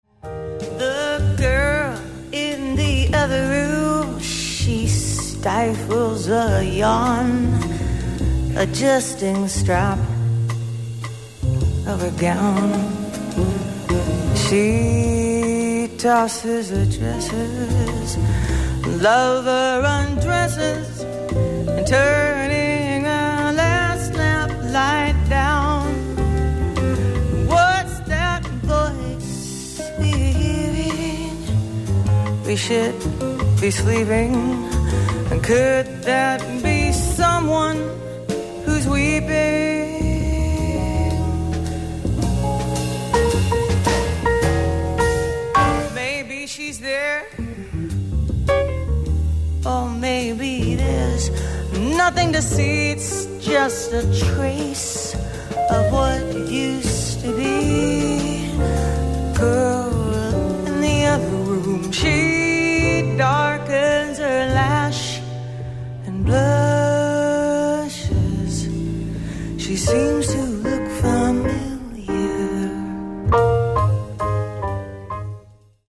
ライブ・アット・メゾン・ド・ラジオ・フランス、スタジオ １０４，パリ 04/12/2004
※試聴用に実際より音質を落としています。